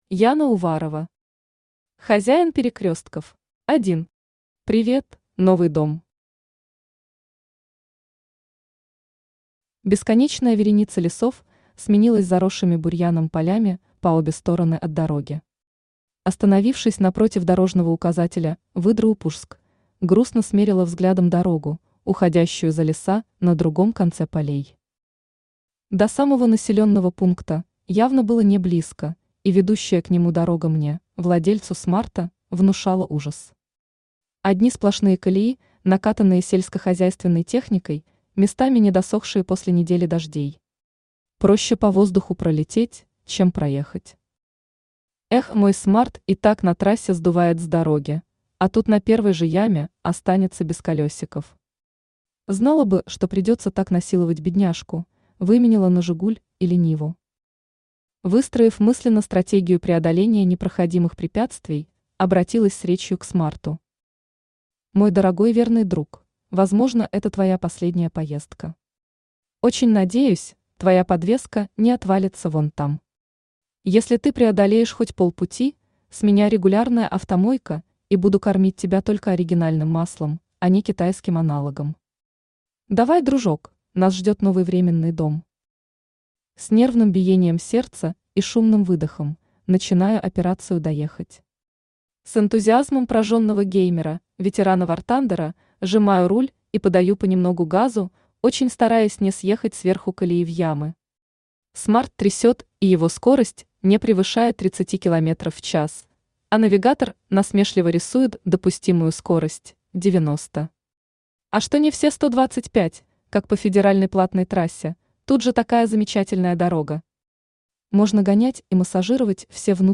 Аудиокнига Хозяин перекрестков | Библиотека аудиокниг
Aудиокнига Хозяин перекрестков Автор Яна Уварова Читает аудиокнигу Авточтец ЛитРес.